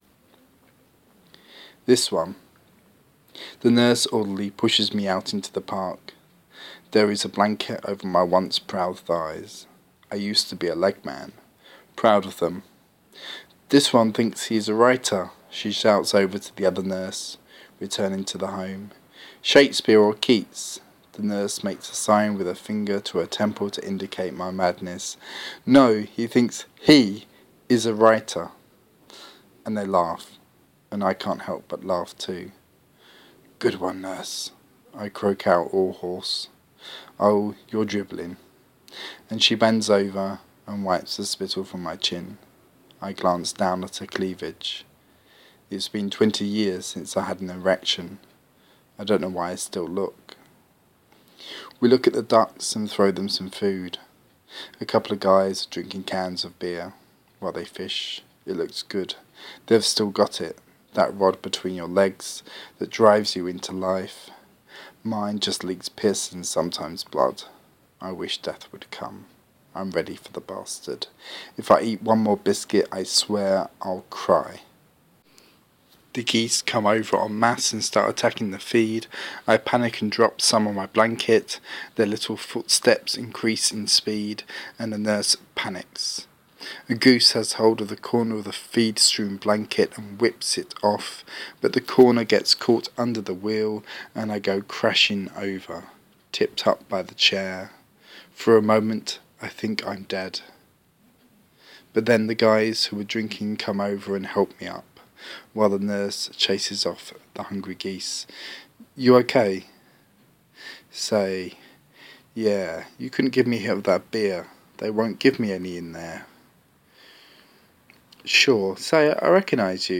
Spoken word story